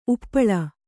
♪ uppaḷa